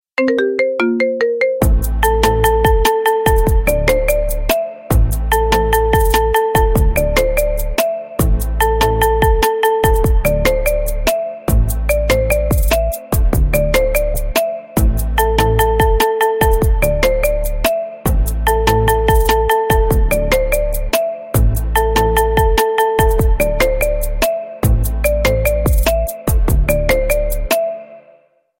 Рингтоны Без Слов » # Рингтоны Ремиксы